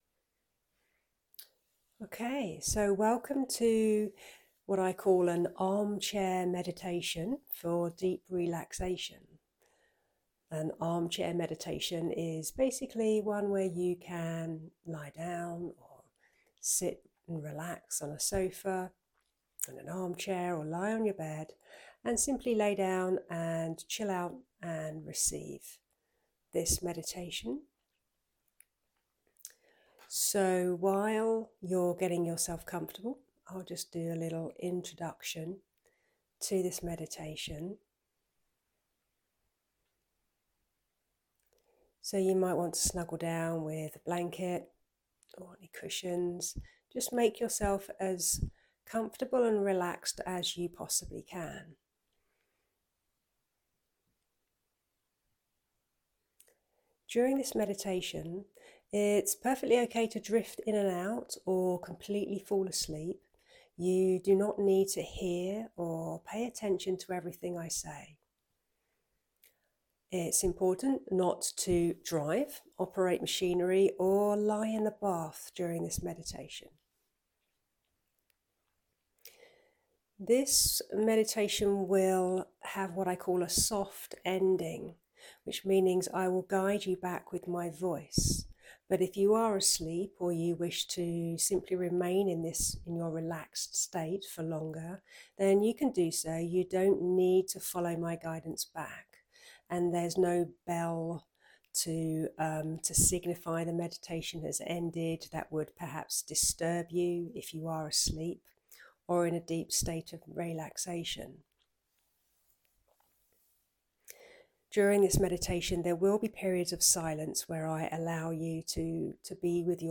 Below is a link to a relaxation meditation I have created which is completely free for you to use as often as you wish.
armchair-meditation-.mp3